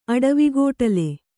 ♪ aḍavigōṭale